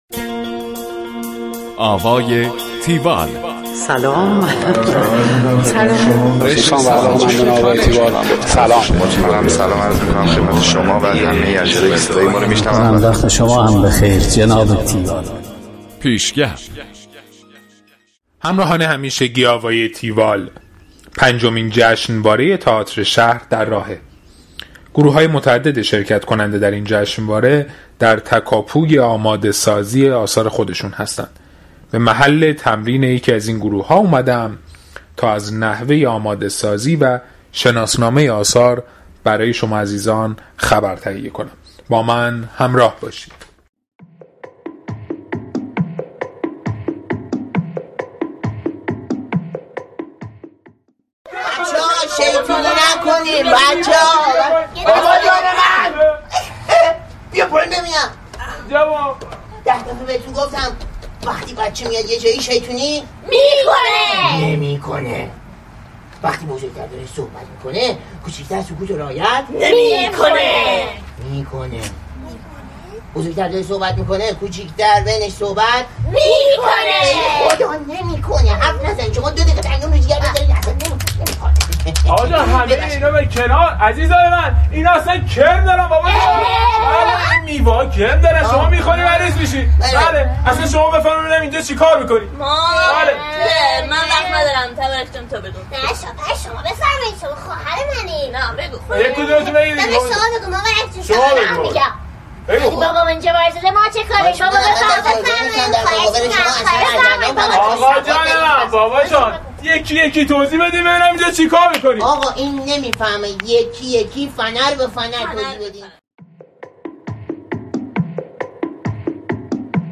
گزارش آوای تیوال از نمایش جادوی عروسک
گفتگو با